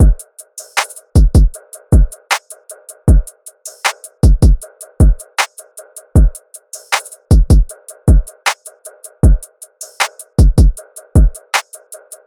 DRUM LOOPS
Connection (156 BPM – Abm)